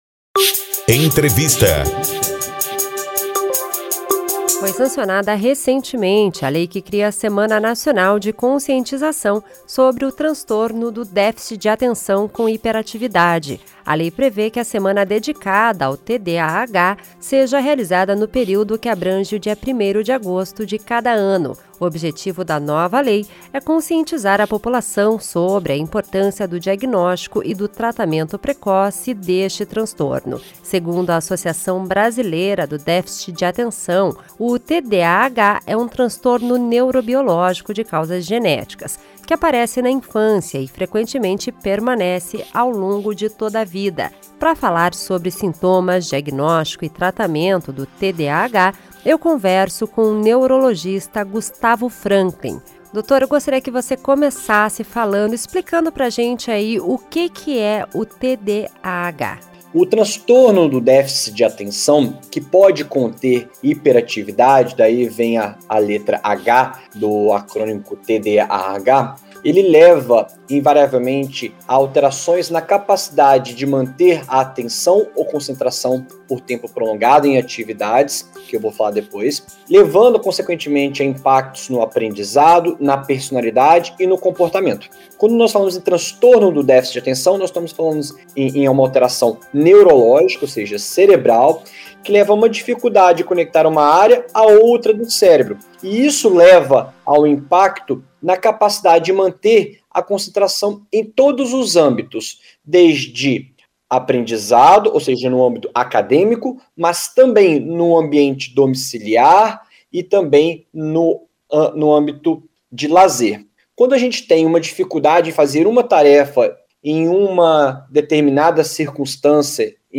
a jornalista
o neurologista